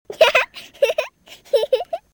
小孩笑 – 果果树育教
babylaugh.mp3